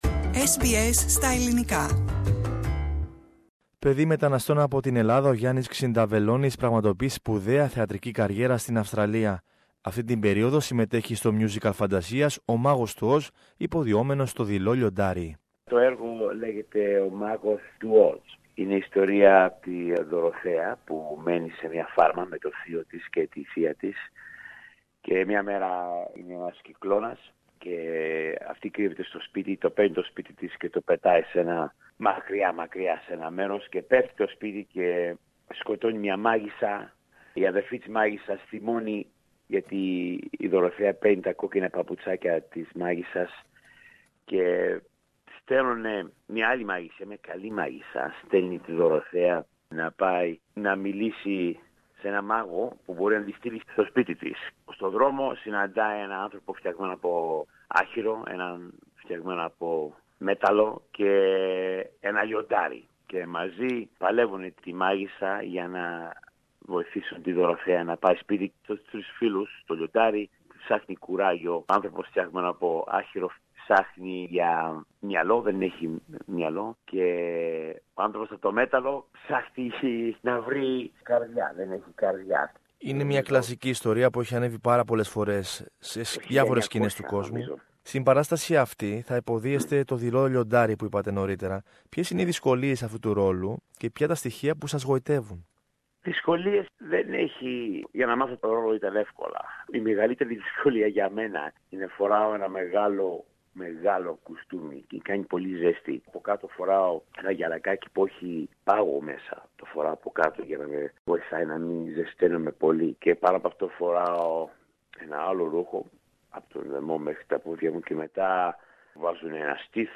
Περισσότερα ακούμε στην συνομιλία